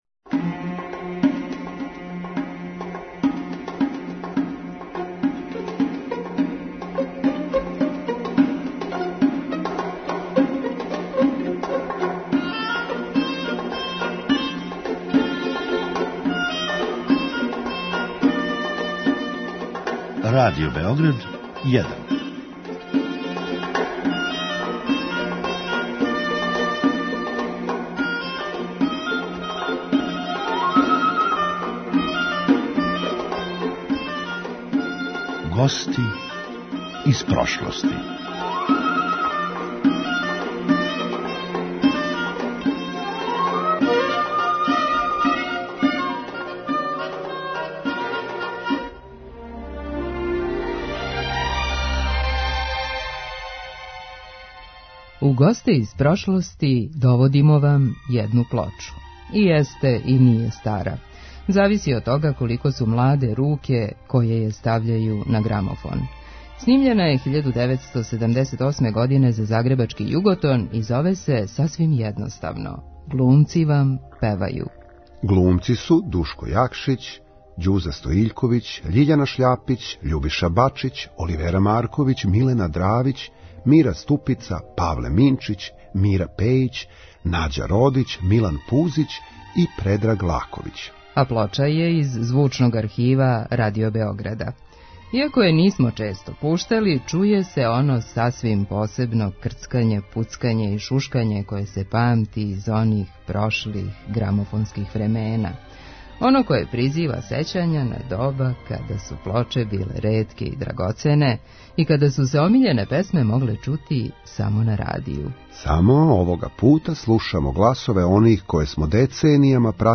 А плоча је из Звучног архива Радио Београда.
Данас за вас певају глумачки бардови.